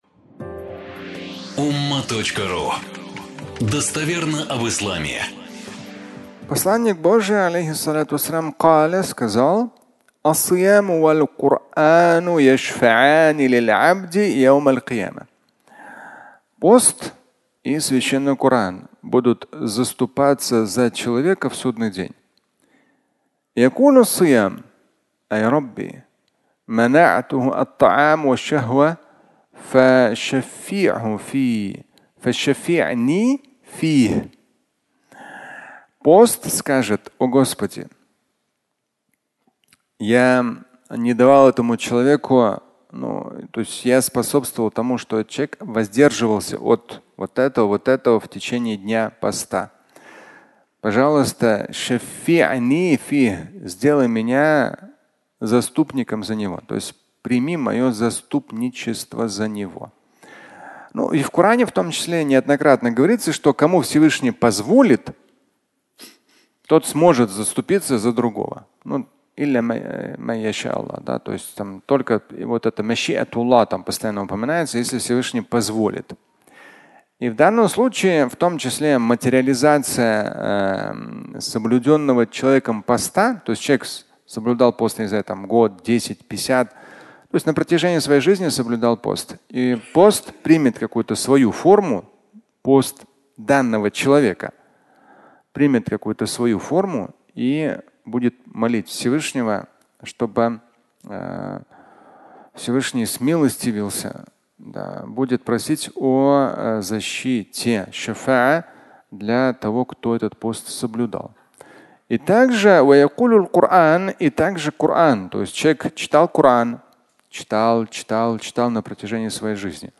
Два заступника (аудиолекция)